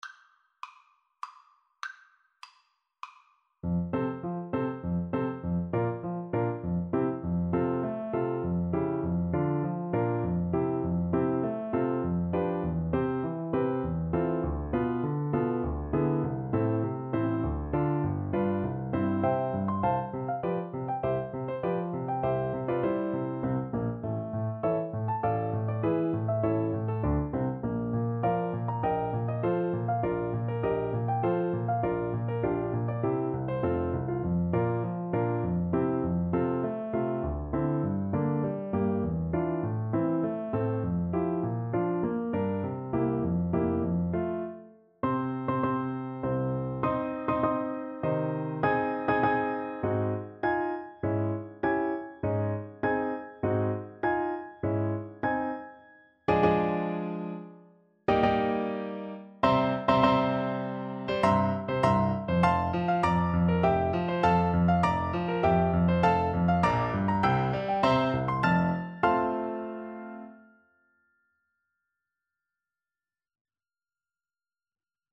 Play (or use space bar on your keyboard) Pause Music Playalong - Piano Accompaniment Playalong Band Accompaniment not yet available transpose reset tempo print settings full screen
F major (Sounding Pitch) (View more F major Music for Cello )
3/4 (View more 3/4 Music)
~ = 100 Tempo di Menuetto
Classical (View more Classical Cello Music)